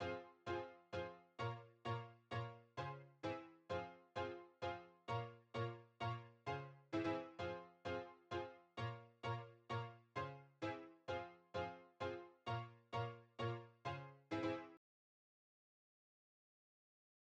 motorsport_piano.wav